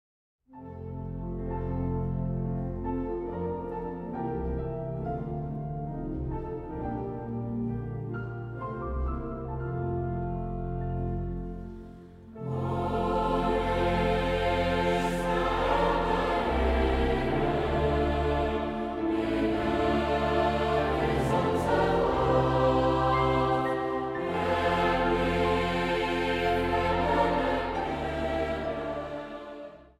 4 stemmen
Zang | Jongerenkoor